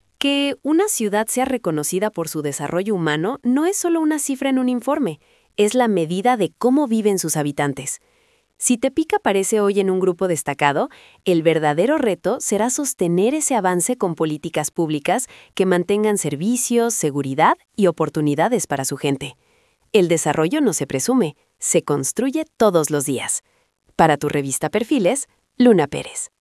🎤 COMENTARIO EDITORIAL